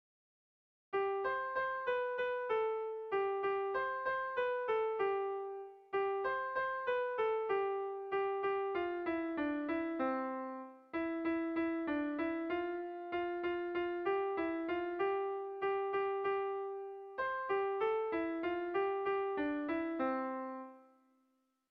Erlijiozkoa
Betelu < Leitzaldea < Iruñeko Merindadea < Nafarroa < Euskal Herria
Zortziko txikia (hg) / Lau puntuko txikia (ip)
ABDE